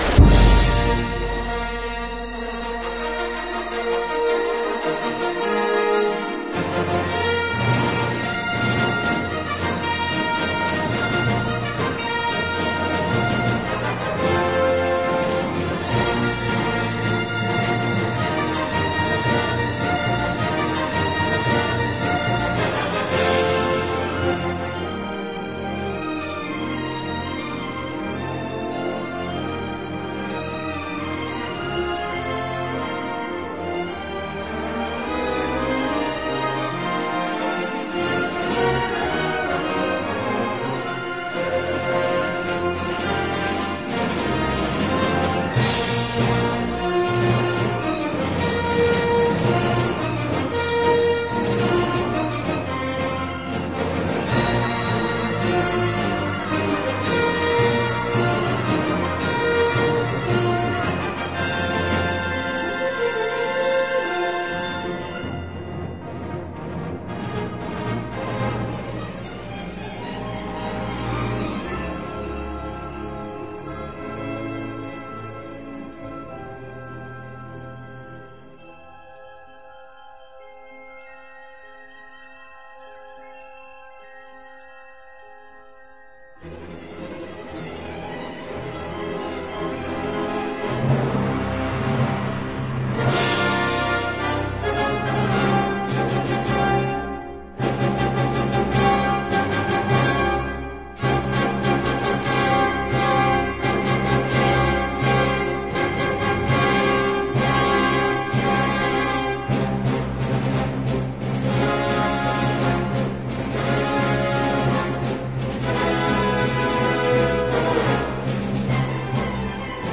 音乐配合着电影快节奏的气氛，呈现一种壮阔蓬勃的气势